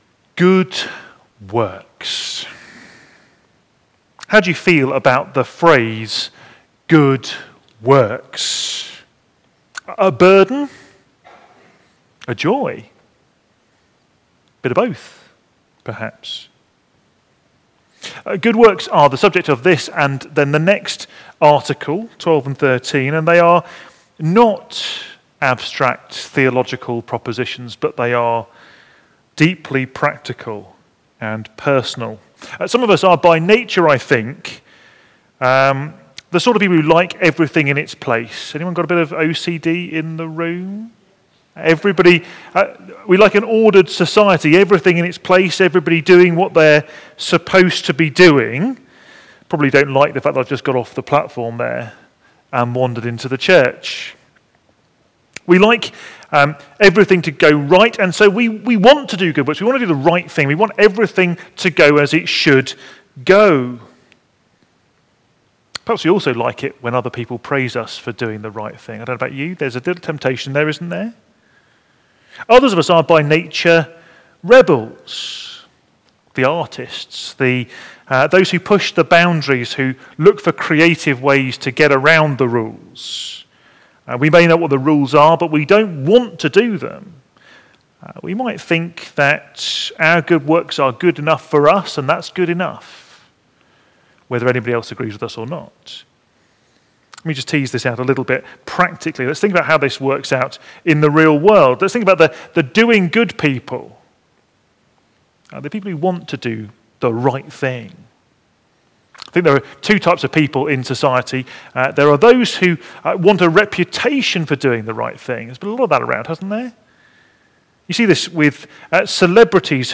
Media Library We record sermons from our Morning Prayer, Holy Communion and Evening services, which are available to stream or download below.
Articles of the Church of England Theme: Article XII Sermon Search